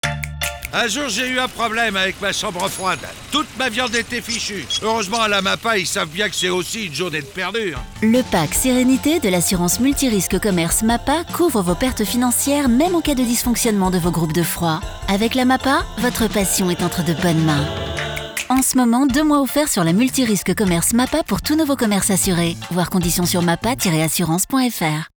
Spot – MRC – Boucherie – Dysfonctionnement groupe de froid